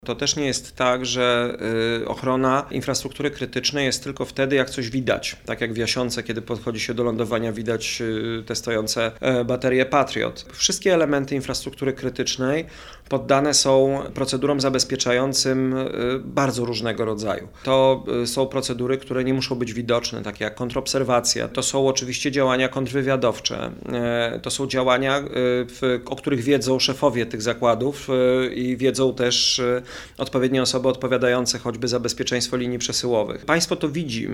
Po spacerze udał się na wiec gdzie prezentował swój program wyborczy. Zapytany o niepewną sytuację na świecie i zagrożenia dla infrastruktury krytycznej w tym gazoportu, Baltic Pipe, portów w Szczecinie i Świnoujściu odpowiedział: